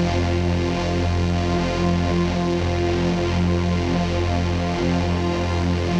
Index of /musicradar/dystopian-drone-samples/Non Tempo Loops
DD_LoopDrone5-F.wav